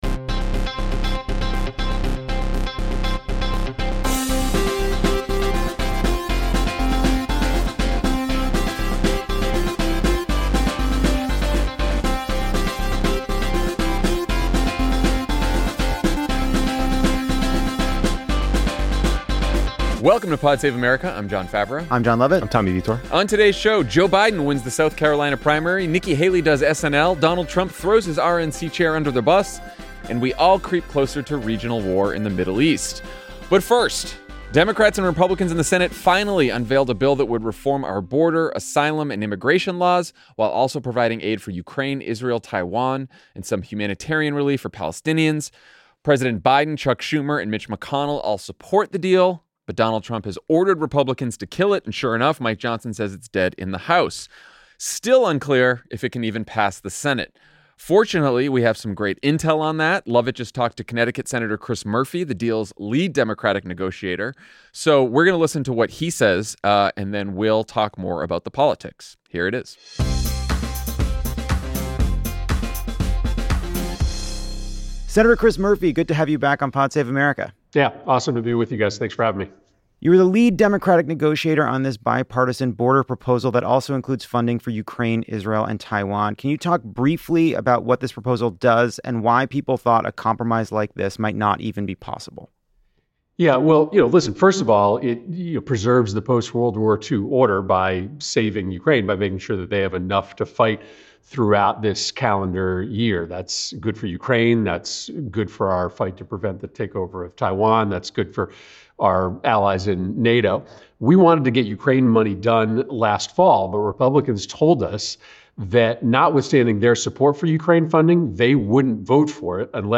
Lead Democratic negotiator Senator Chris Murphy joins the pod to talk about the bipartisan senate border security bill, GOP push-back, and sending conditional aid to Israel. Later, Trump throws RNC Chair Ronna McDaniel under the bus while praising Xi Jinping.